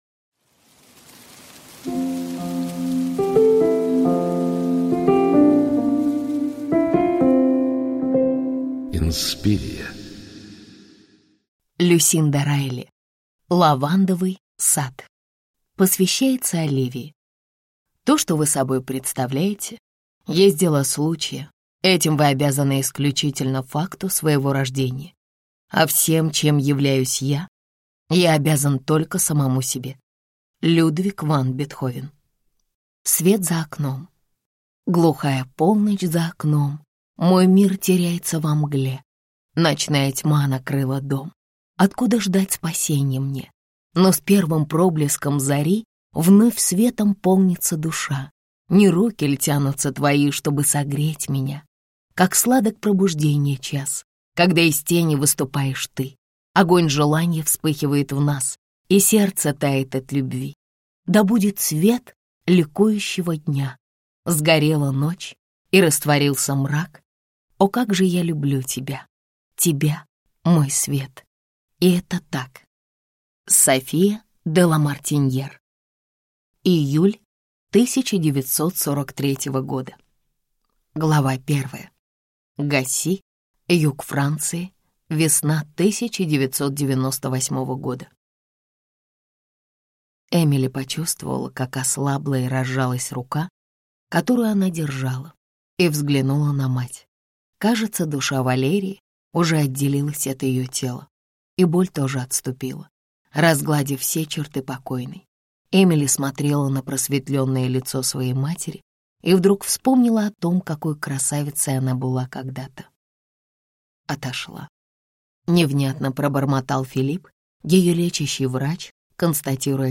Аудиокнига Лавандовый сад | Библиотека аудиокниг